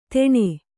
♪ teṇe